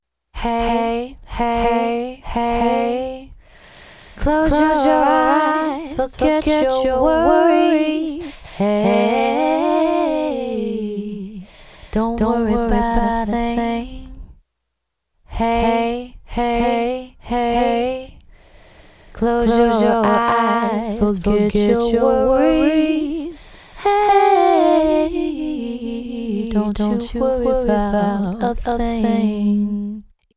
4声の美しいハーモニーとリズミカルなディレイを生み出す、直感的なピッチシフター
Fem Vox Micropitch Slap
Fem-Vox-Micropitch-Slap.mp3